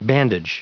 Prononciation du mot bandage en anglais (fichier audio)